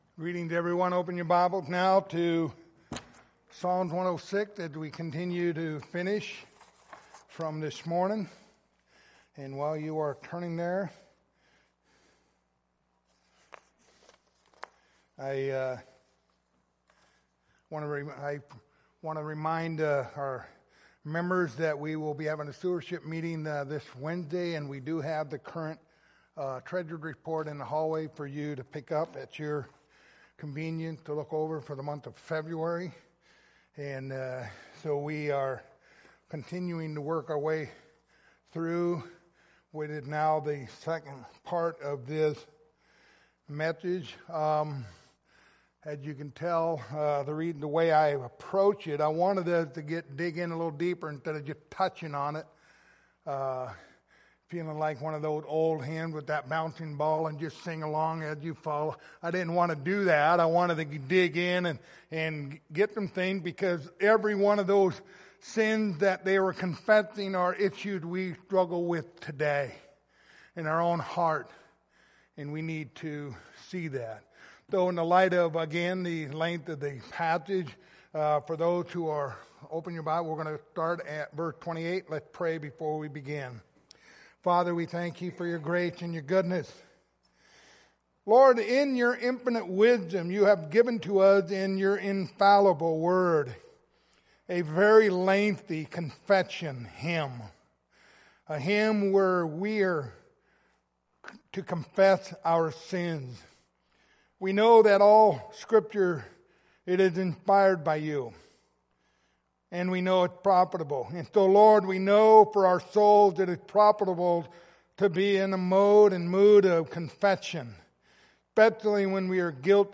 Passage: Psalms 106:28-46 Service Type: Sunday Evening